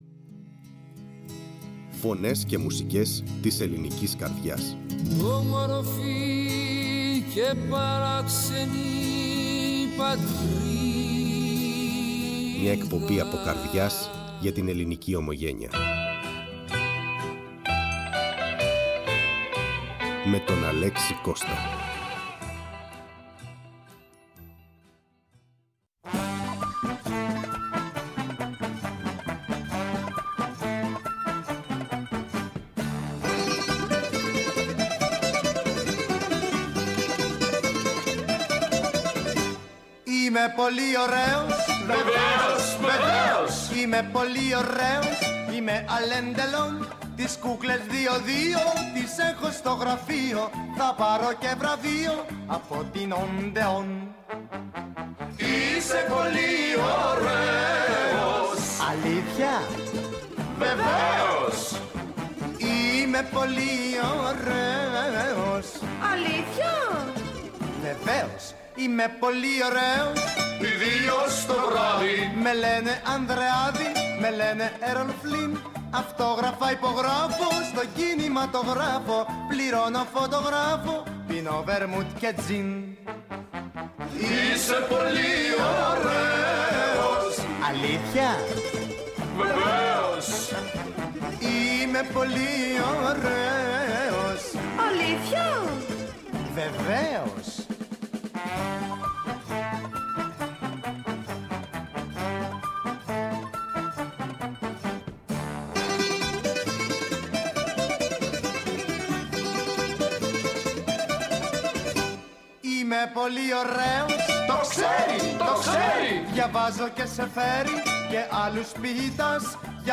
Η ΦΩΝΗ ΤΗΣ ΕΛΛΑΔΑΣ Φωνες και Μουσικες ΜΟΥΣΙΚΗ Μουσική ΣΥΝΕΝΤΕΥΞΕΙΣ Συνεντεύξεις